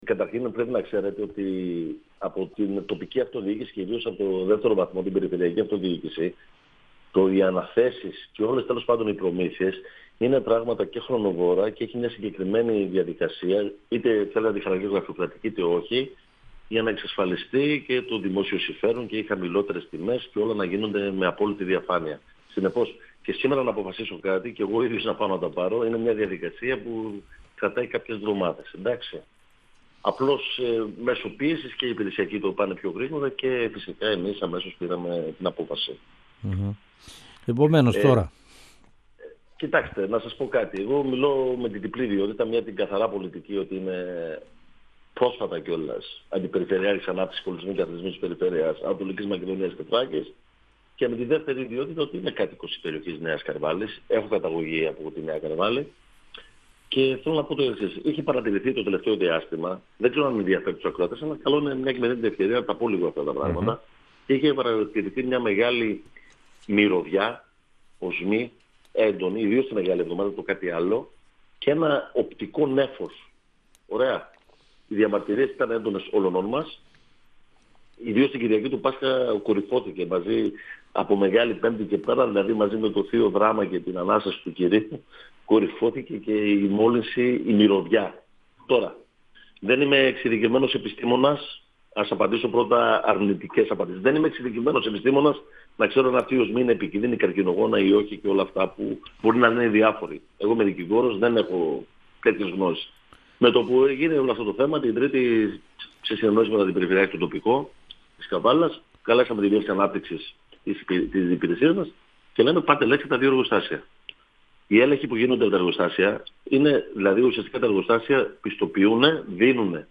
Ο αντιπεριφερειάρχης Ανάπτυξης, Αλέξανδρος Ιωσηφίδης, στον 102FM του Ρ.Σ.Μ. της ΕΡΤ3
Συνέντευξη